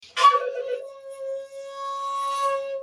Shakuhachi 58